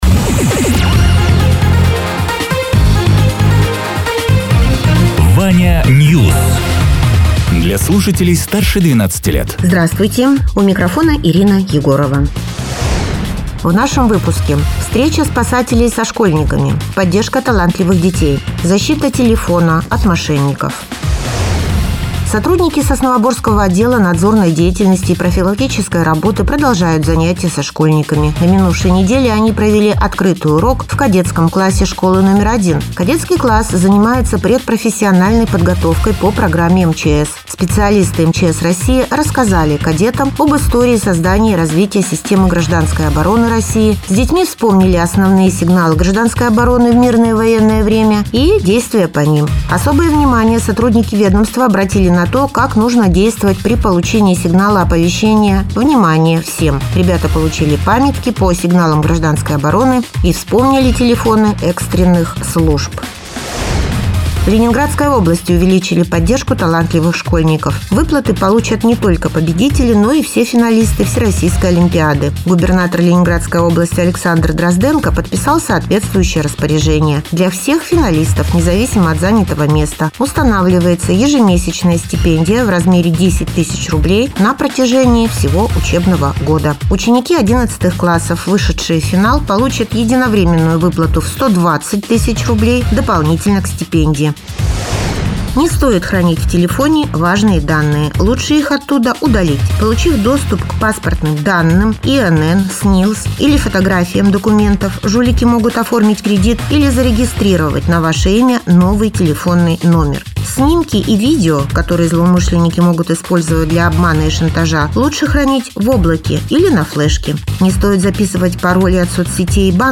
Радио ТЕРА 29.03.2026_08.00_Новости_Соснового_Бора